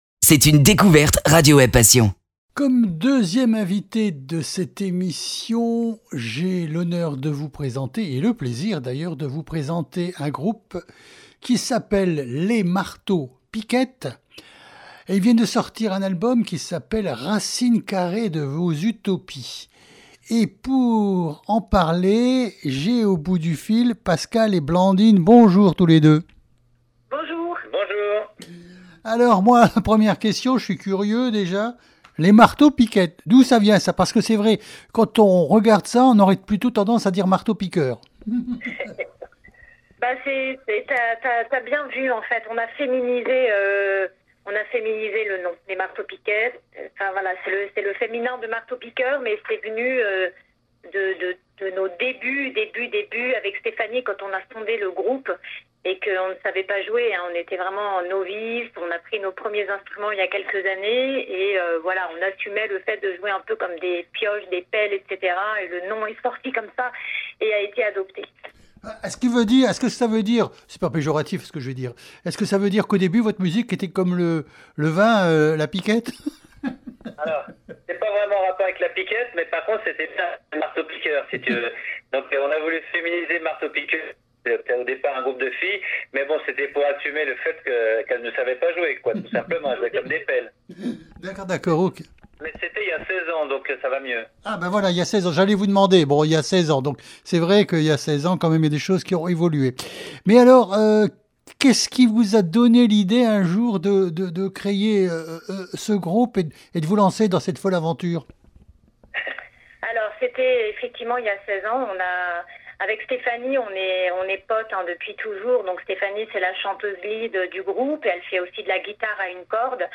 Interview sur radio Web-passion (18/03/2021)